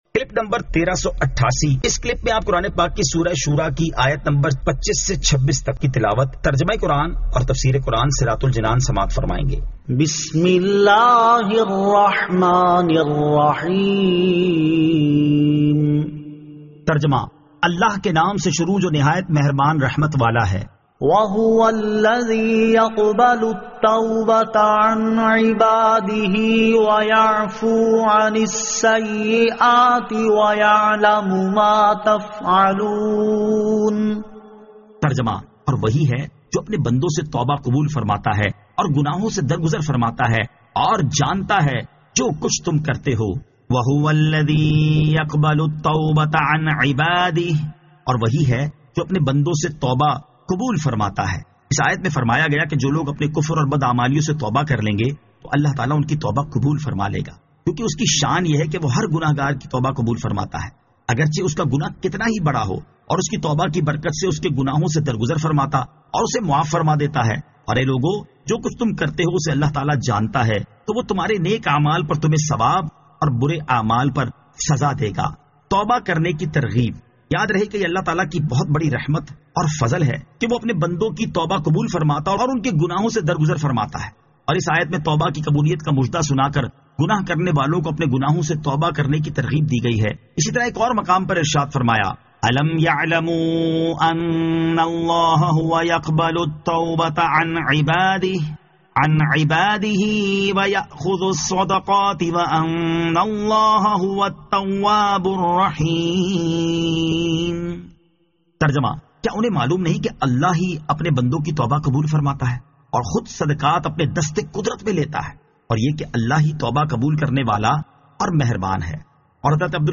Surah Ash-Shuraa 25 To 26 Tilawat , Tarjama , Tafseer
2023 MP3 MP4 MP4 Share سُوَّرۃُ الشُّوٗرَیٰ آیت 25 تا 26 تلاوت ، ترجمہ ، تفسیر ۔